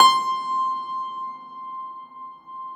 53e-pno18-C4.aif